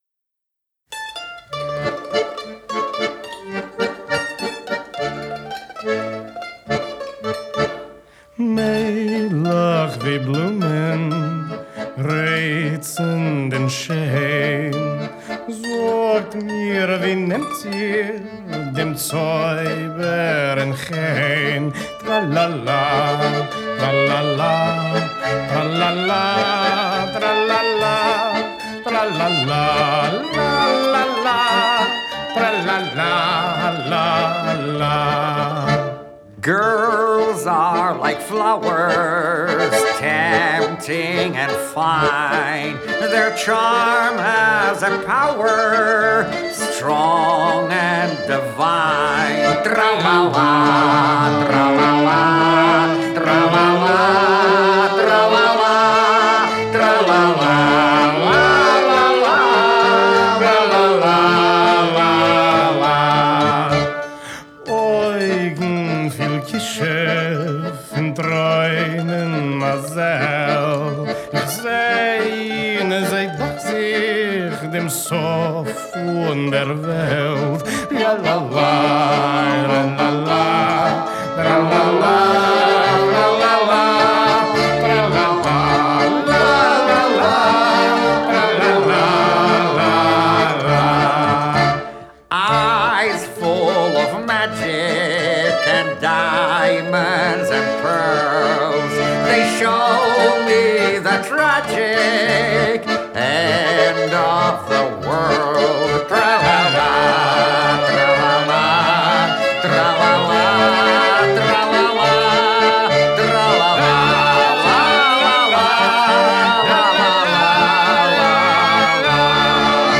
Genre: Folk